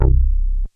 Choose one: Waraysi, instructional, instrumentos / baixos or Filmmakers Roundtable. instrumentos / baixos